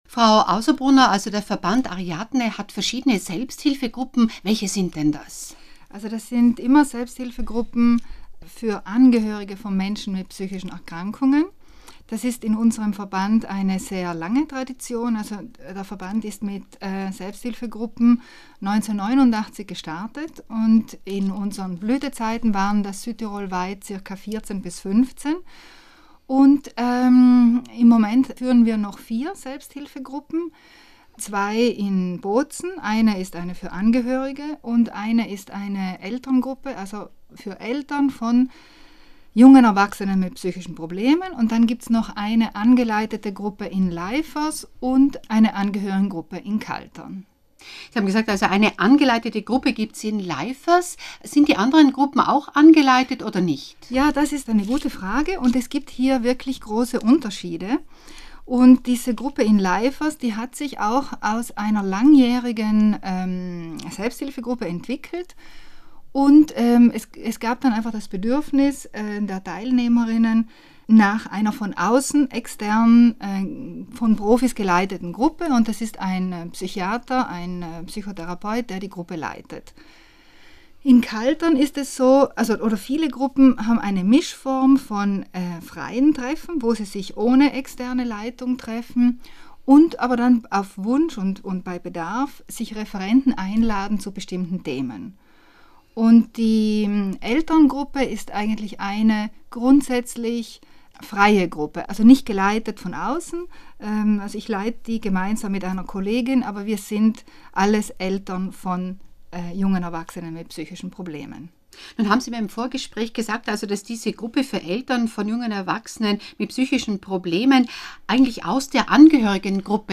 Interview_Radio-Grüne-Welle_SHG-Mai-2018.mp3